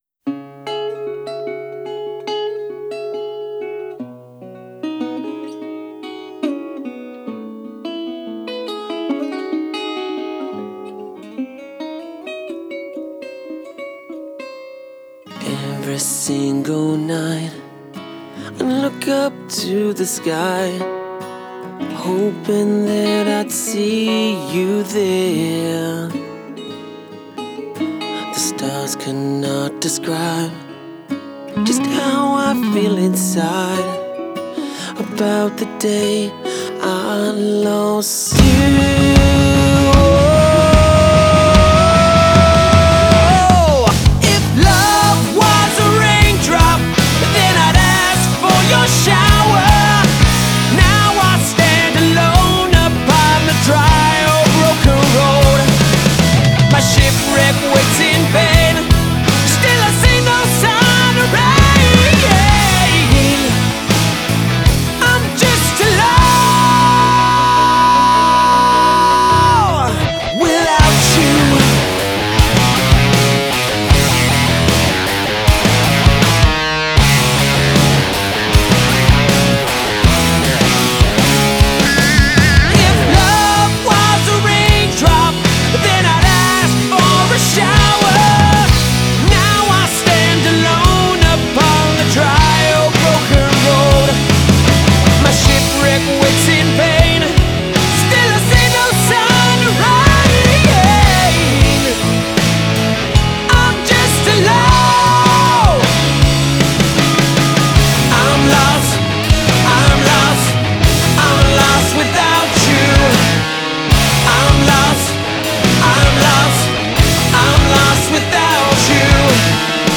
Australian rock band